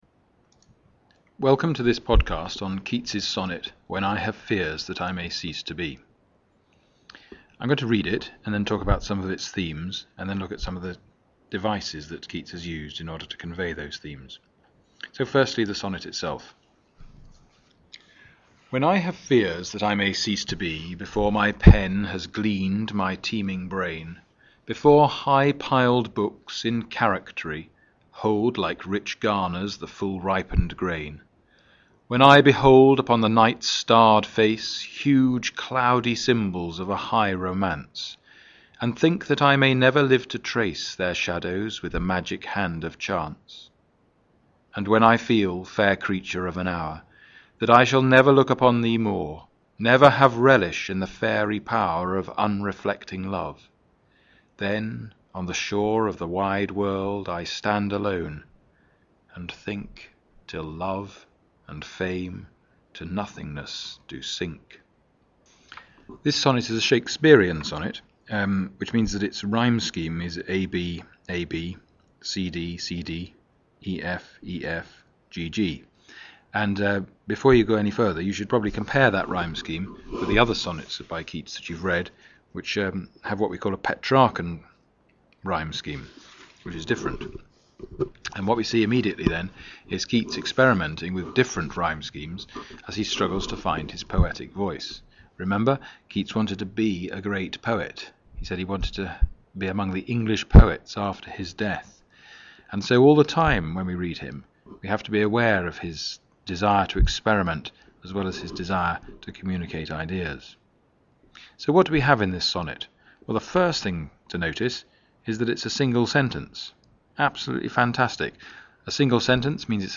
A series of short talks about English Literature texts set for GCSEs and A levels in the UK